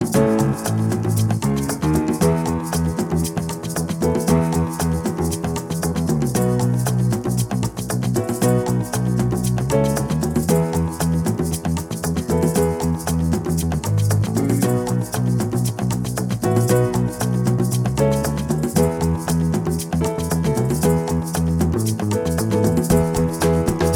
Minus Solo Guitar Rock 6:19 Buy £1.50